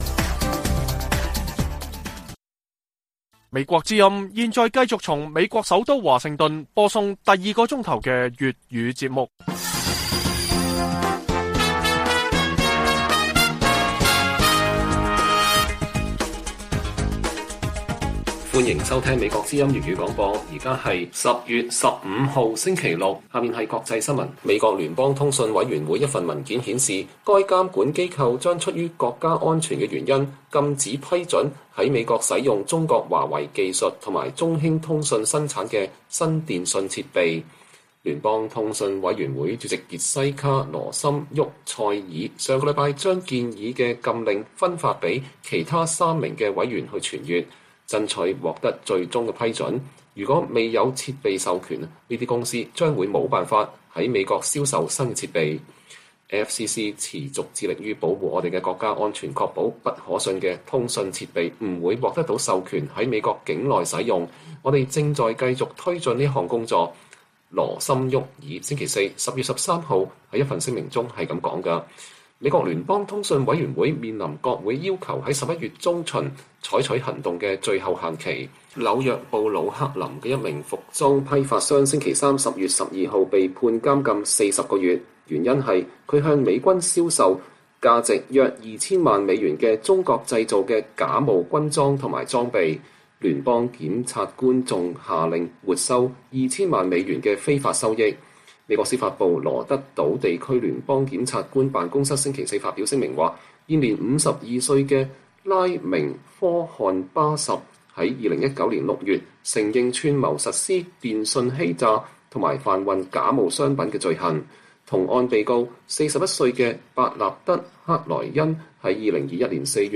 粵語新聞 晚上10-11點 ： 中共20大後 台海或進入關鍵期 美國威懾拒阻至關重要